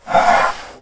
battlemage_attack5.wav